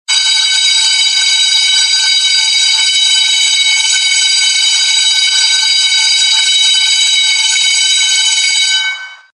School Bell
school-bell-2.mp3